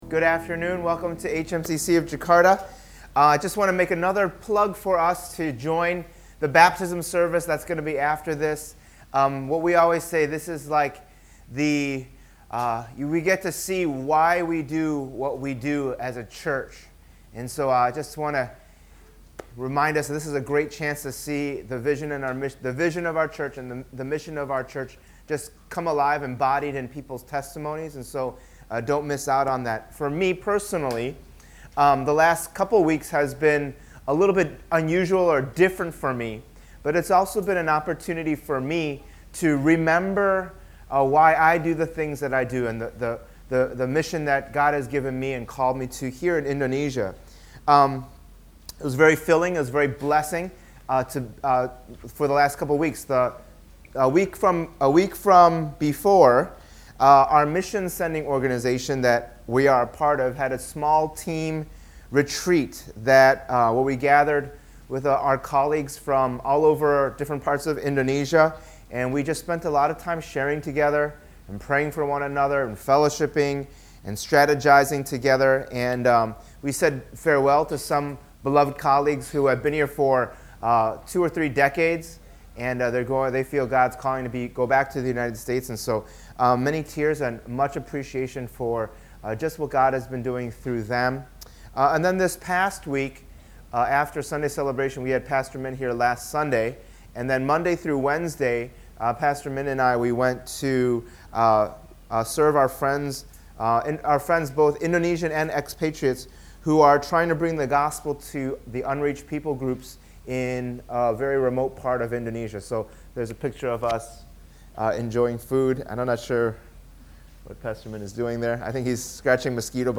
Download Audio Subscribe to Podcast Audio The Kingdom Series This sermon series called “The Kingdom” will go through the books of 1 and 2 Samuel.